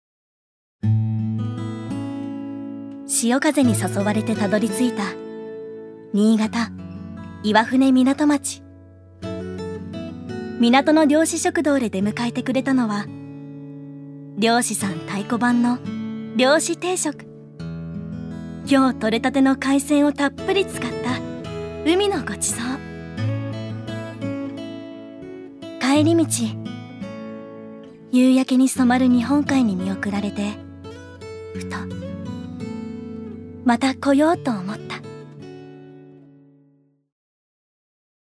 預かり：女性
ナレーション１